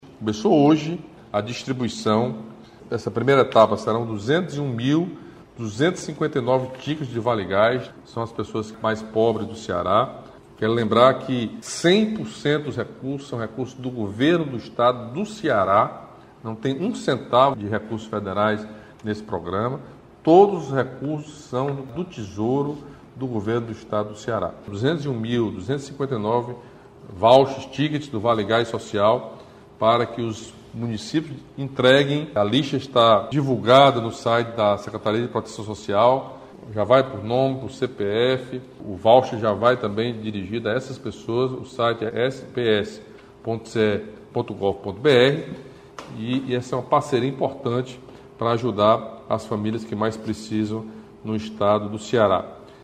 A informação foi confirmada pelo governador Camilo Santana, nesta terça-feira (14), durante conversa semanal com a população por meio das redes sociais.